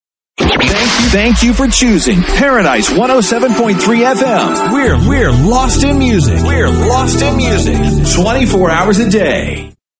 TOP 40